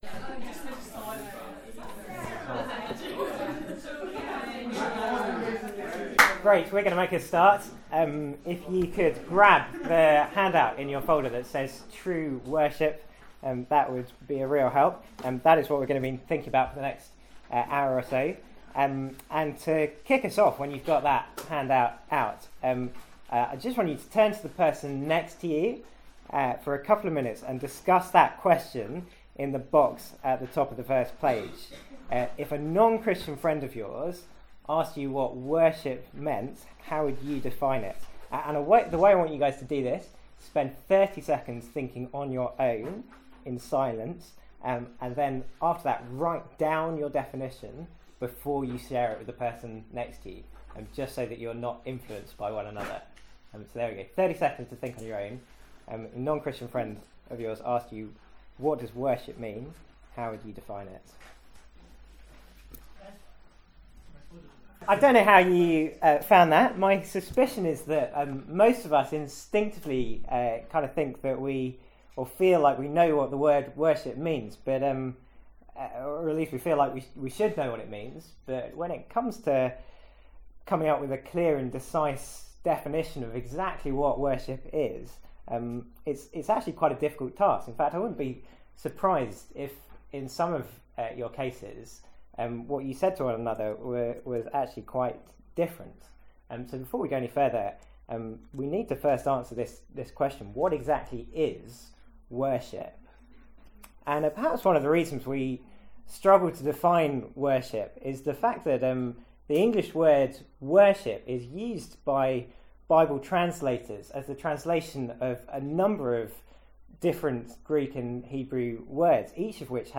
Seminar from MYC15.